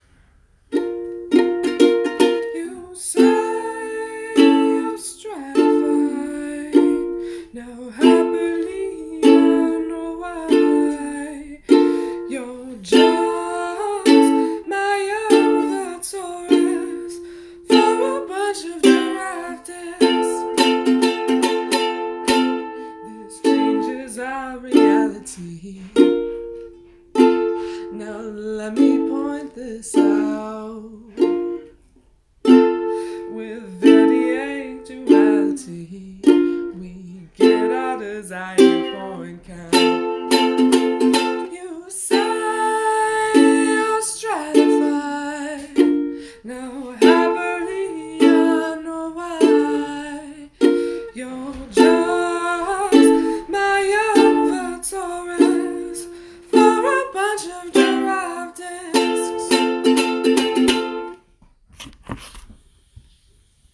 Ukulele Songs from the TamagaWHAT Seminar